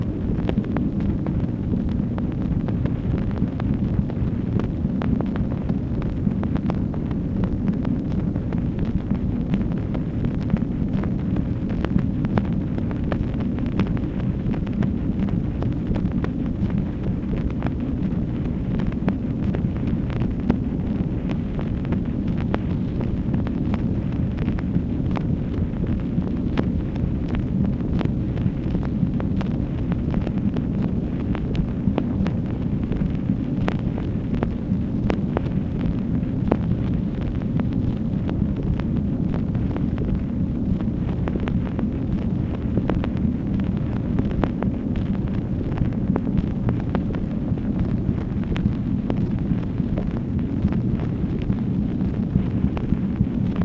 MACHINE NOISE, August 21, 1965